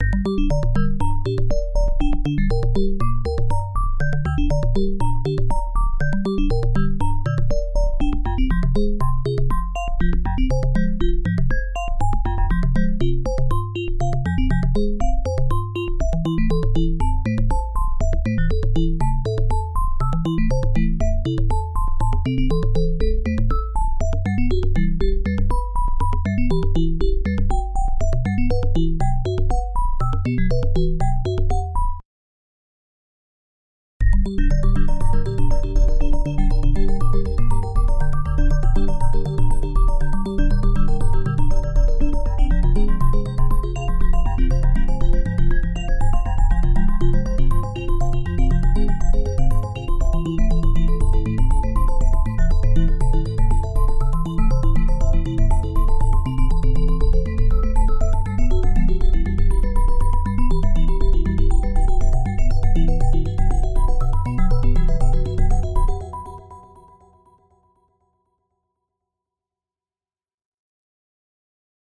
Most of these samples don't reflect the typical OPL3 sound, but should demonstrate the possibilites of the MIDIbox FM in conjunction with external Fx gear.
The very first demo song. 4 voices are playing an E-Piano like sound, the morphed arpeggio sequence is played live with the MIDIbox SEQ. From 0:00-0:32 you will hear the raw sound output of the OPL3, at 0:34 it has been enriched with a chorus and delay effect.